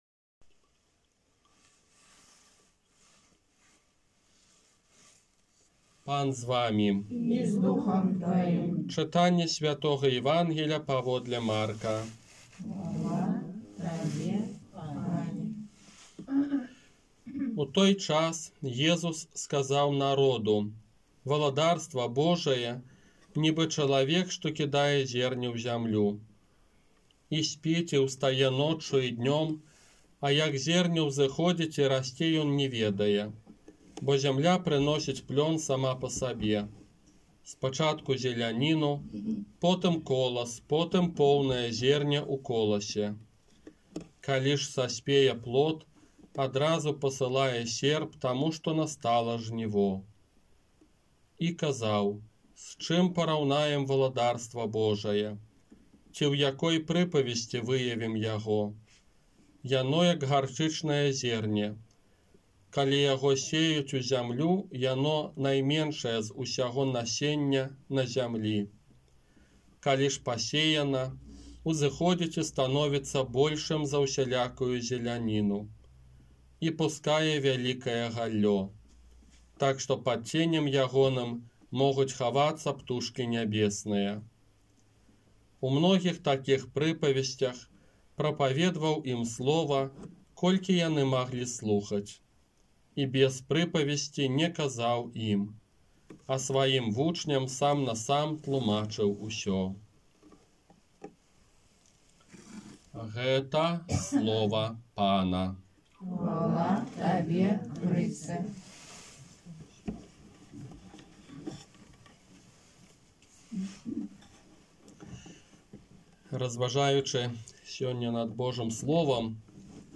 Казанне на адзінаццатую звычайную нядзелю 13 чэрвеня 2021 года